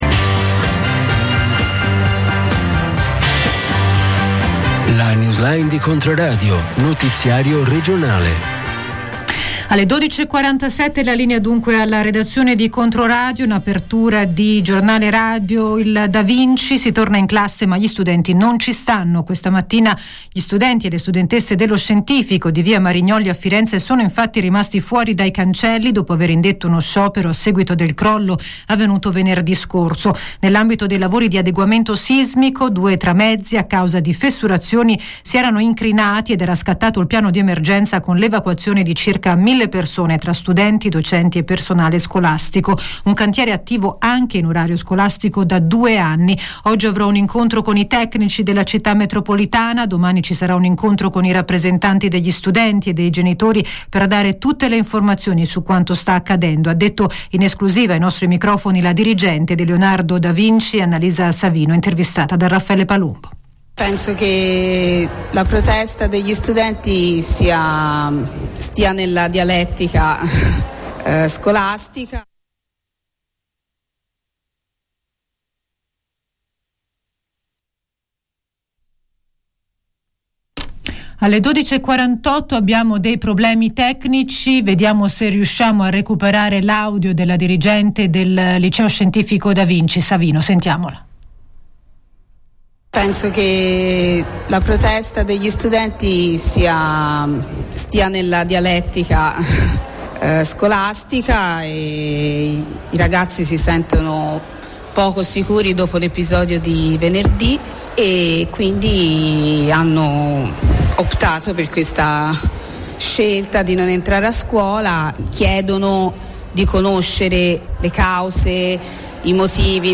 Notiziario regionale